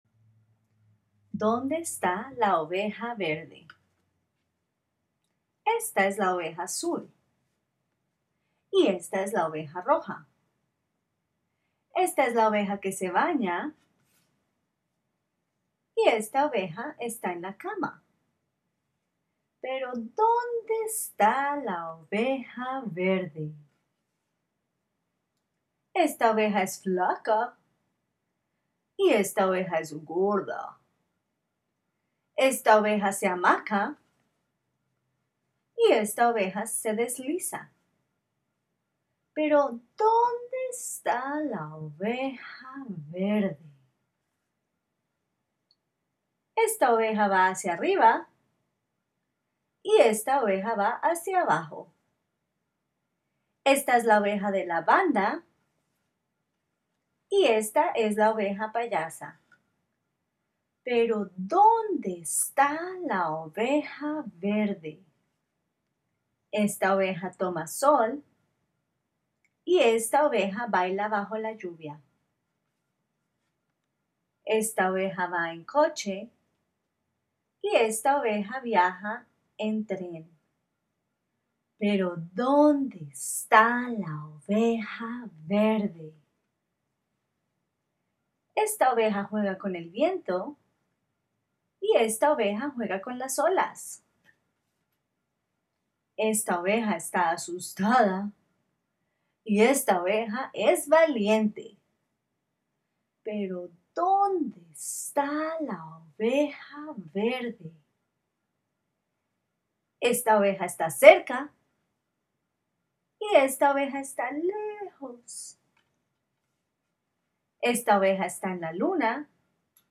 HERE to listen to the audio I have recorded for this book.